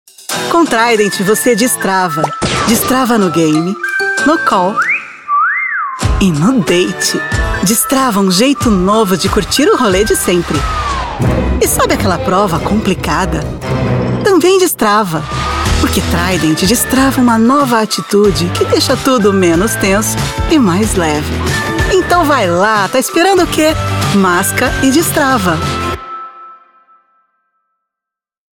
Meine Stimme ist tief, sexy und einladend. Heimstudio und schnelle Lieferung.
Sprechprobe: eLearning (Muttersprache):
My voice is deep, sexy and inviting.